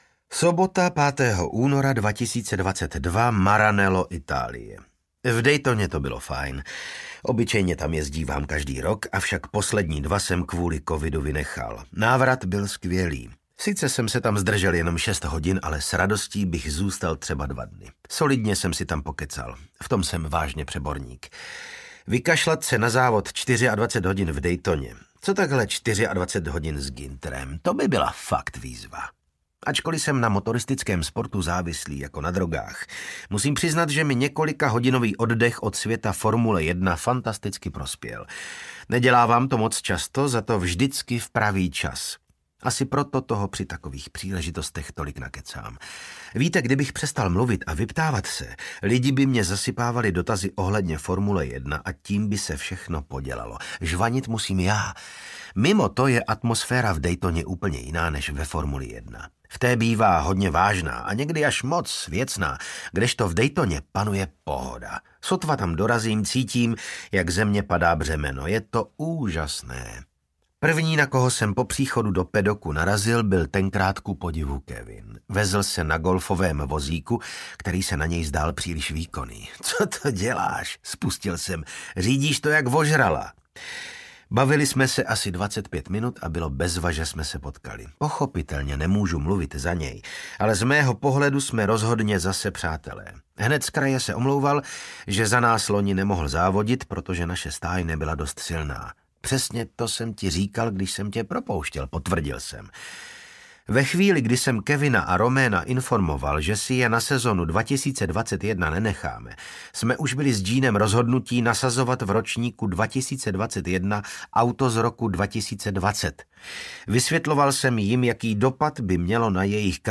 Audiokniha - Přežít a závodit: Jeden rok šéfa stáje F1 | ProgresGuru
Čte: Vasil Fridrich